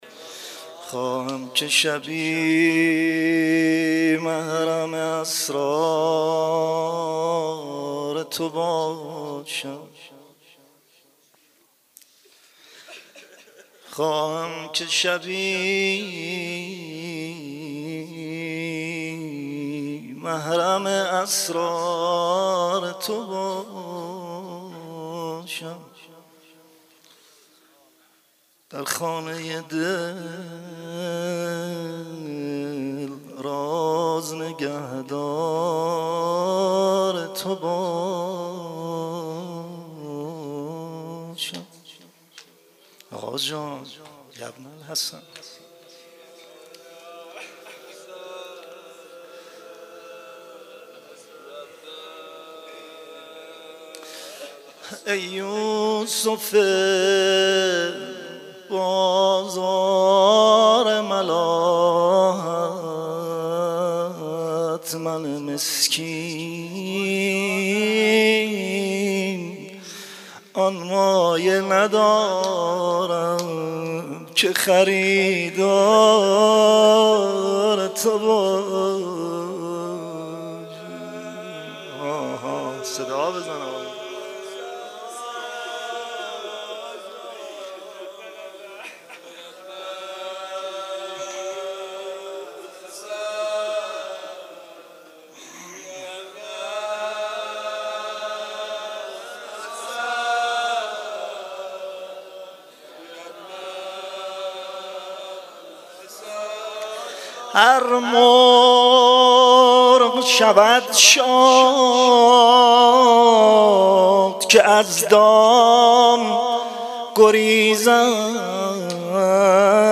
مناجات شب دهم محرم(عاشورای حسینی)